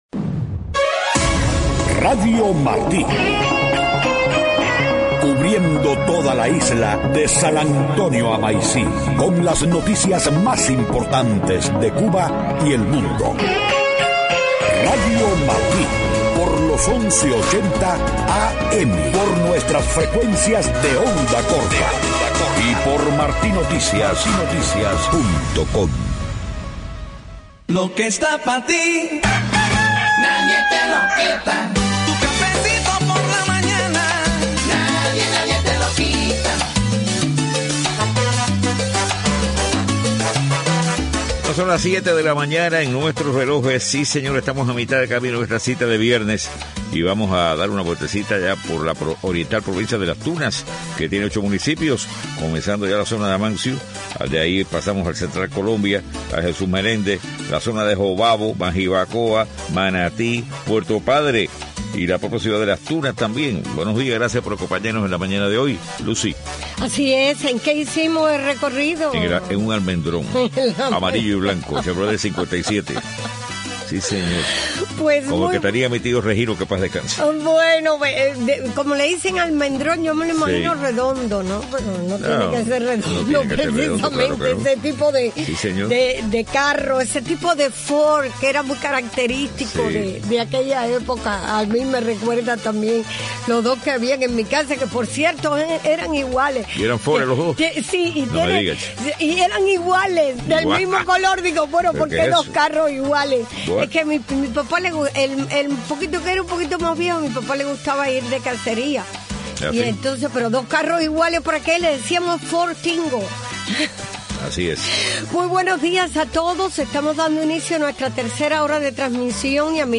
7:00 a.m Noticias: 32 organizaciones de EEUU firmaron carta que pide al Congreso apoyar la apertura de una embajada en Cuba. Israel afirma que dos de sus ciudadanos están cautivos en Gaza, uno de ellos en manos de Hamás. Alto diplomático de EEUU asegura que Washington no intervendrá en la crisis del Gobierno de Guatemala.